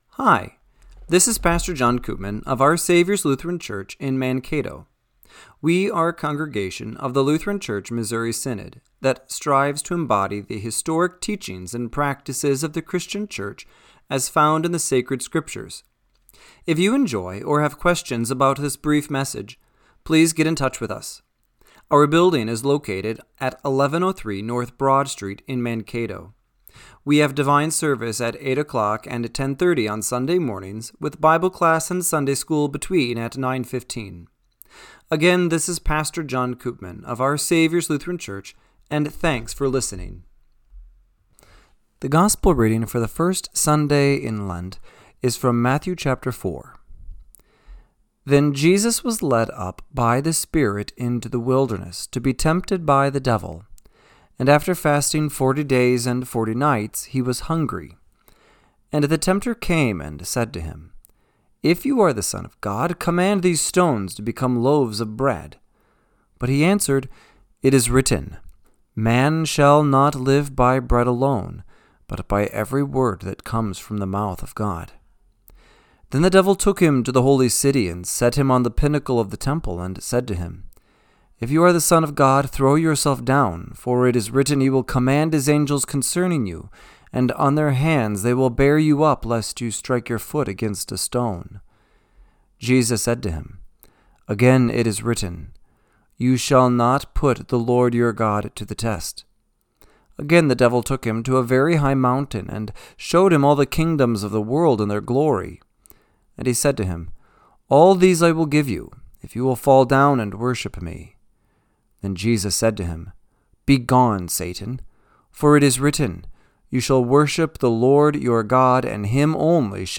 Radio-Matins-2-22-26.mp3